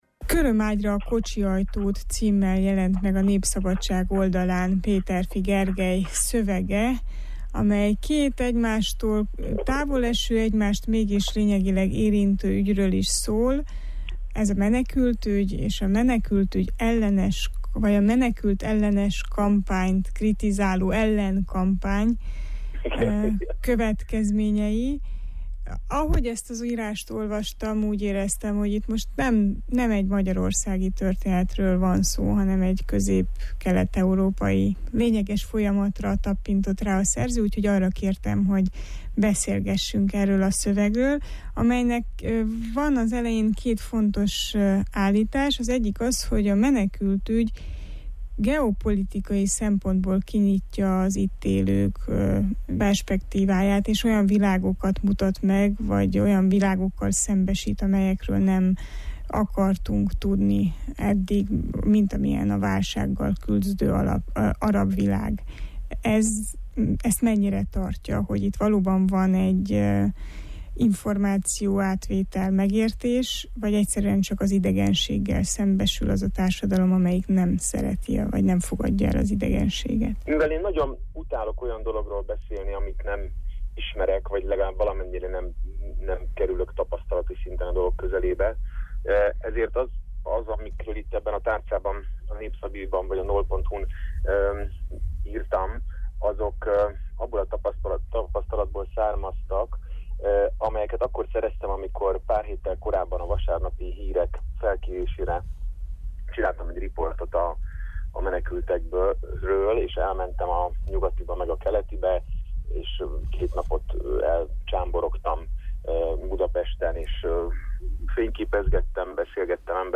Ki az idegen, és mit jelent ma tanulni, tényleg társadalmi-történelmi korszakhatárhoz érkeztünk? Erről beszélgettünk.